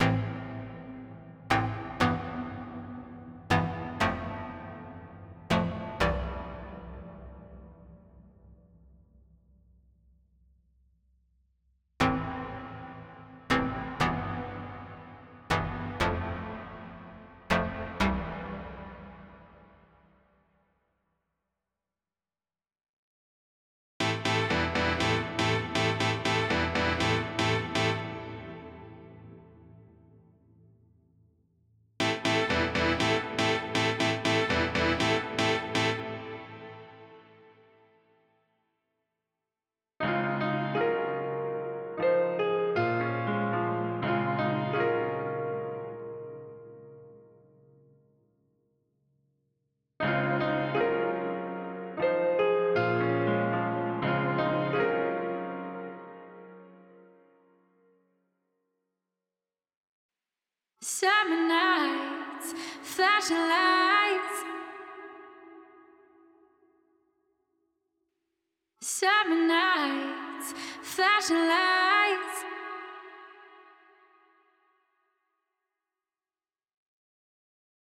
Попробовал посравнивать с LiquidSonics Seventh Heaven Pro, подобрал наиболее близкий плэйт пресет.
Первые семплы - тойзы, вторые - 7 небо. вав 24 бита Вложения 7 HEAVEN PRO vs SOUNDTOYS LITTLE PLATE.wav 7 HEAVEN PRO vs SOUNDTOYS LITTLE PLATE.wav 19,7 MB · Просмотры: 106